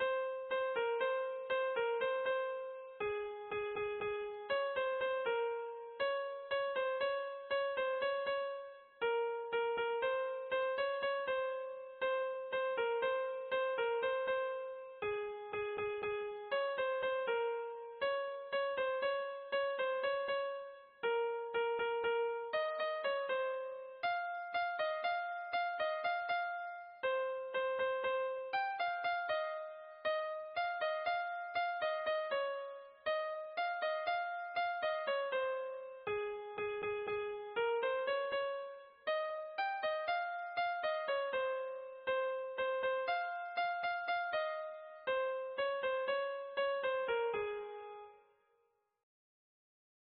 Kontakizunezkoa
A-B-C